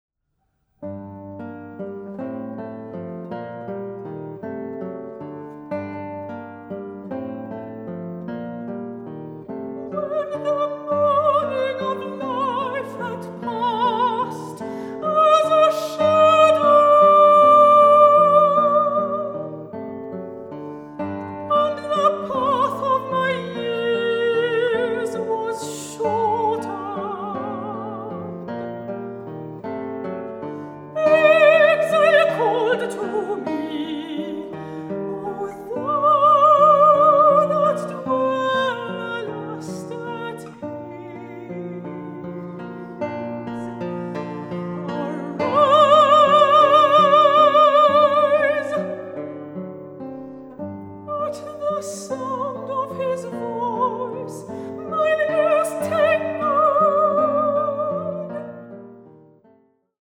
Guitar
Vocals